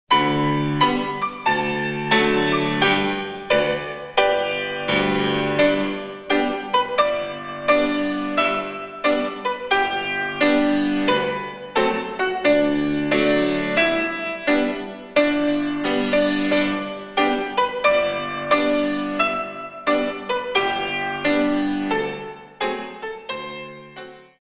In 2
32 Counts